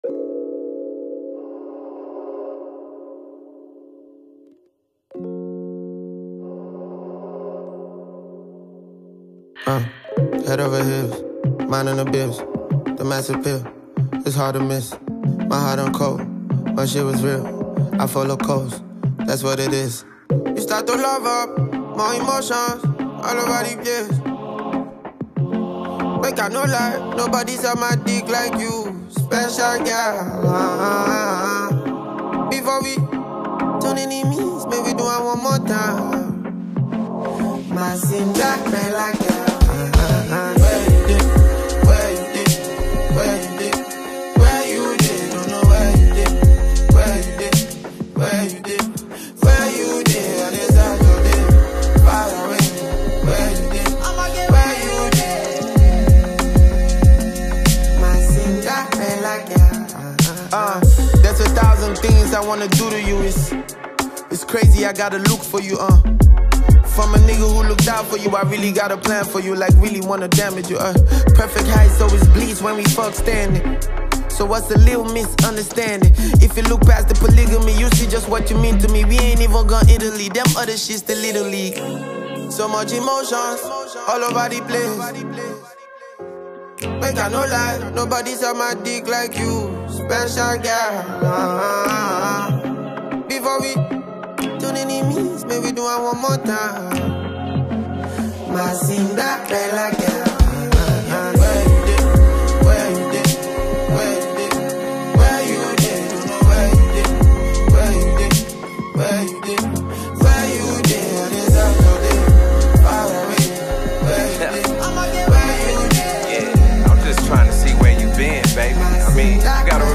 Nigerian rapper and singer